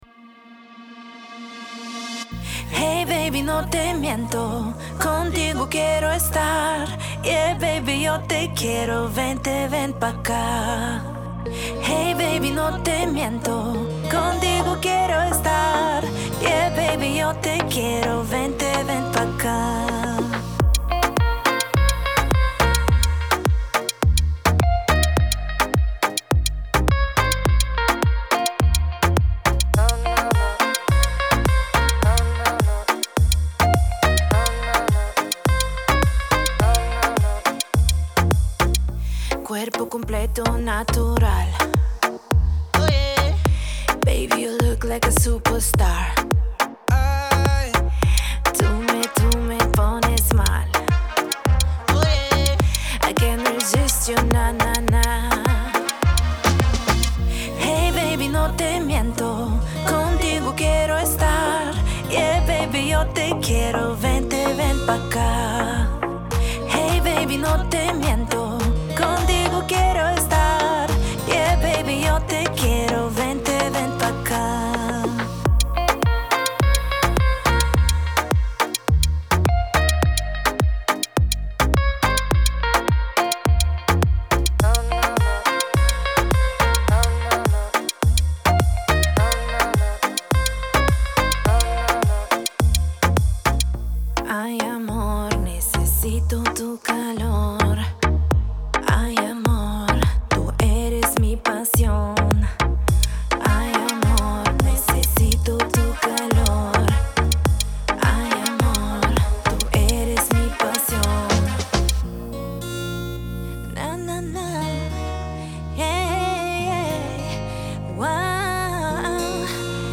зажигательная композиция в жанре латино